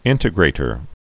(ĭntĭ-grātər)